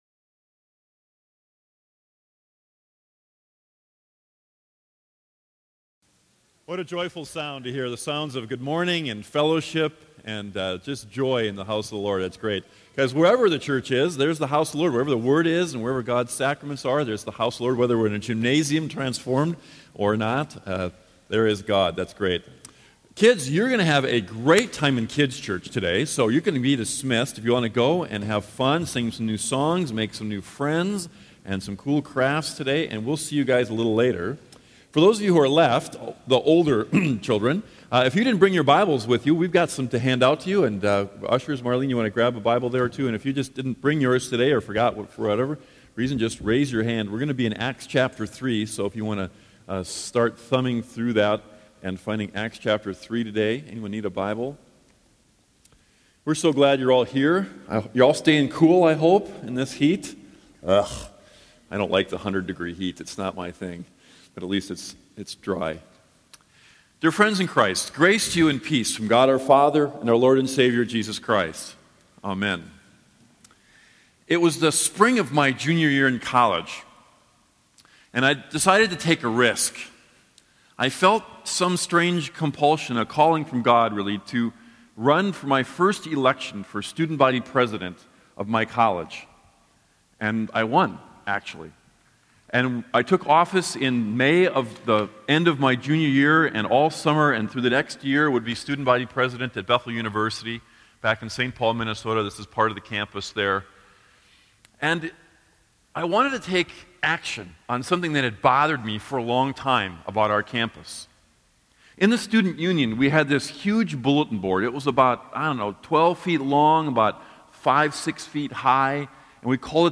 The truth is that God has made all of us leaders. Here is a rerun of one of my favorite past sermons on leadership.